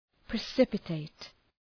Προφορά
{prı’sıpətıt}